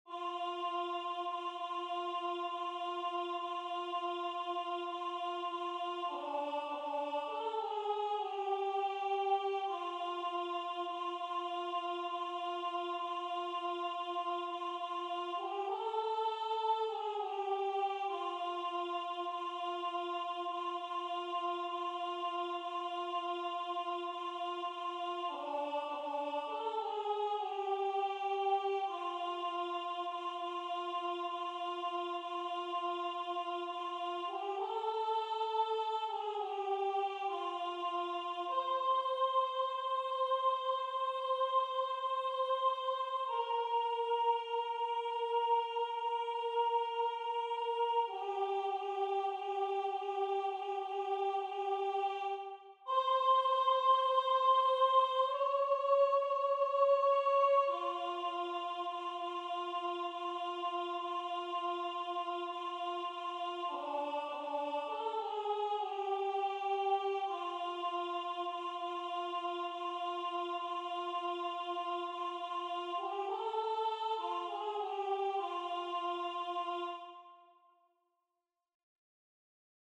MP3 rendu voix synth.